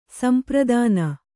♪ sampradāna